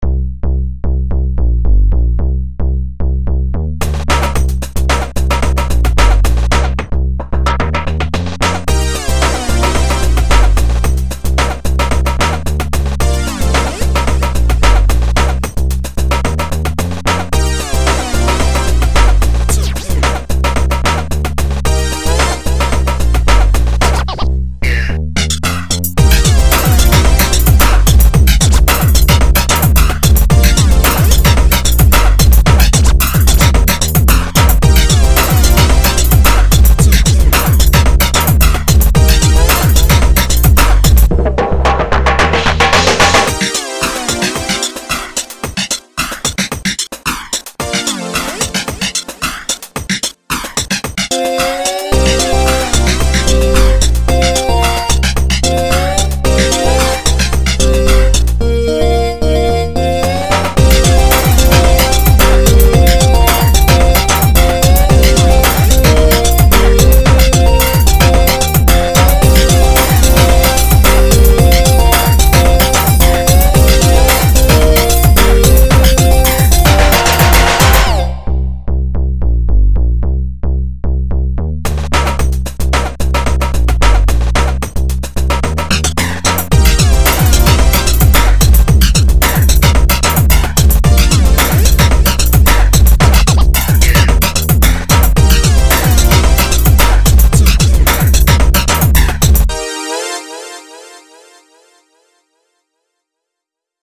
【.ジャンル.】　techno pop
ボタンを押すと特徴的な音がなり、楽しませてもらいました。
ただ、中盤に二つのシンセがごっちゃになり、
本当に氏らしい、地味なんですが丁寧。
リズムトラックが音・リズム共に凄くて感動モノでした。